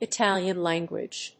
/ɪˈtæljən(米国英語)/